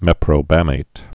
(mĕprō-bămāt, mĕ-prōbə-)